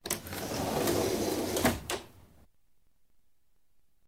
doorsopen.wav